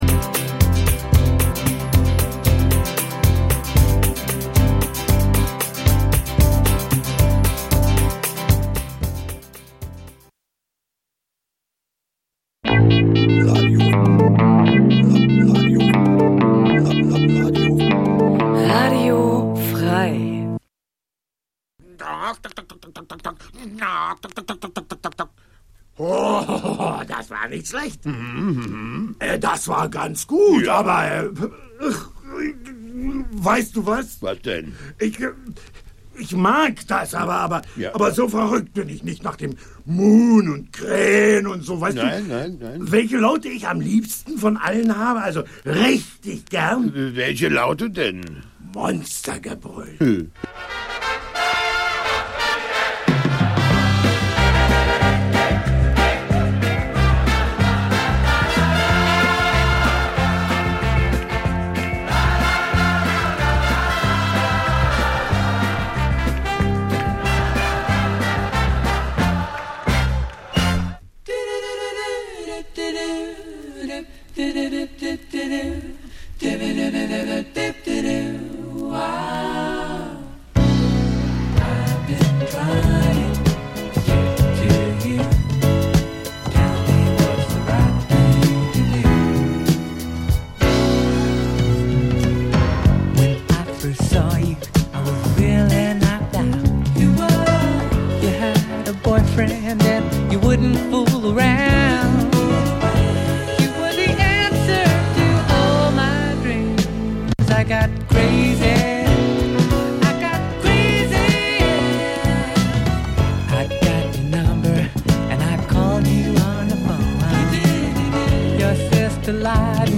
Easy Listening Dein Browser kann kein HTML5-Audio.